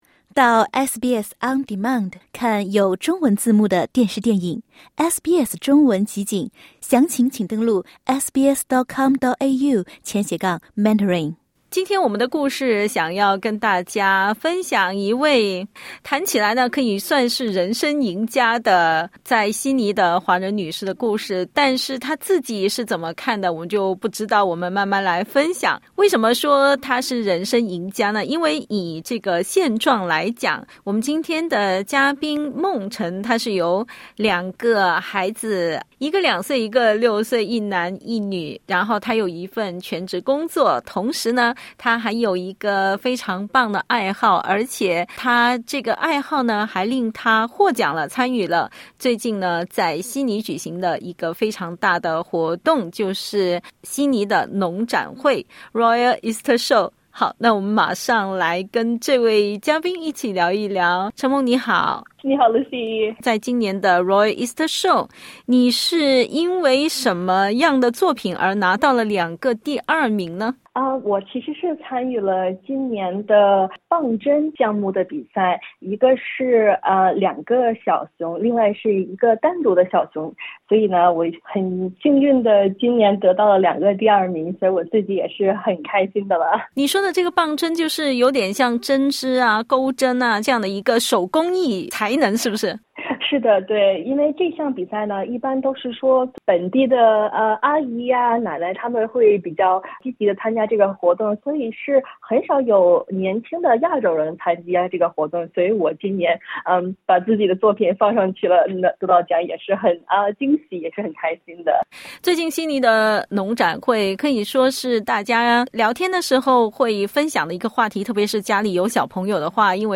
（点击音频收听详细采访）